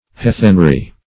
Search Result for " heathenry" : The Collaborative International Dictionary of English v.0.48: Heathenry \Hea"then*ry\ (-r[y^]), n. 1.